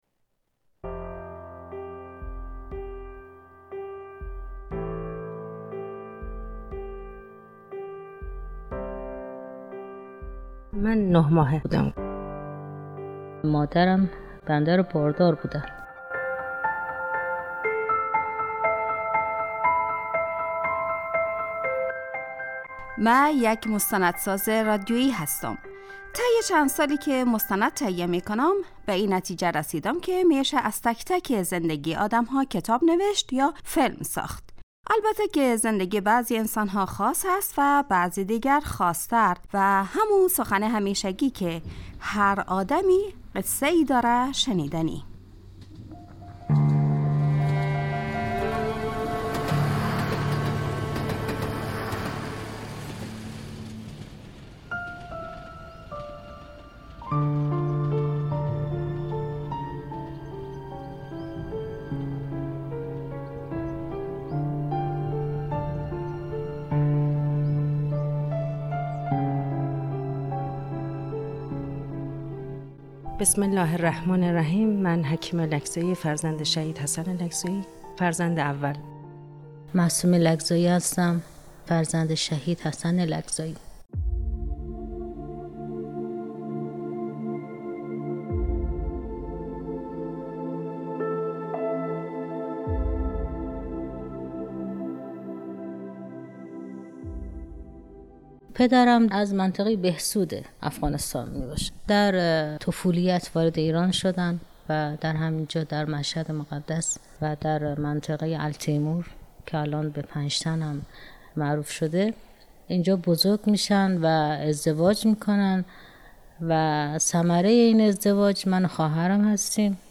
عشق بی مرز مستندی است درباره شهید افغانستانی انقلاب اسلامی ایران ،